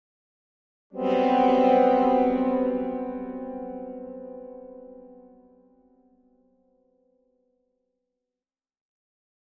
Symphonic Strings Suspense Chord 3 - Lower